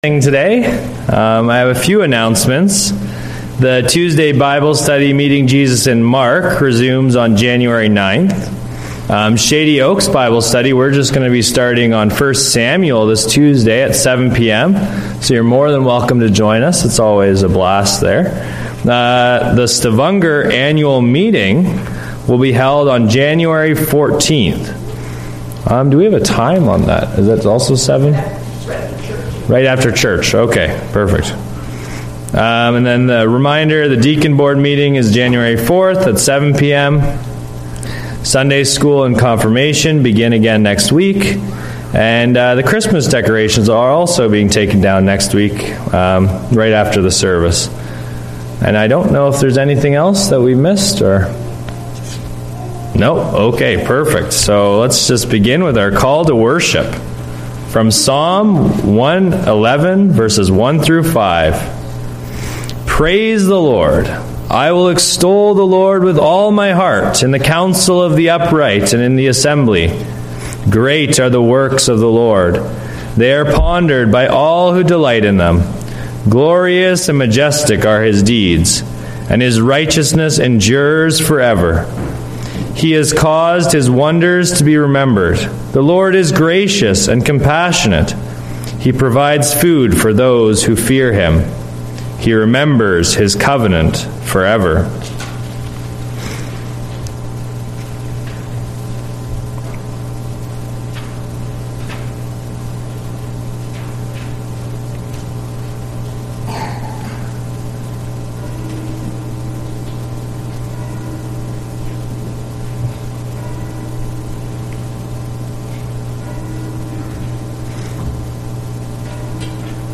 Hymn Sing
From Series: "Sunday Worship"